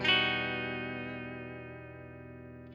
007CHORDS3.wav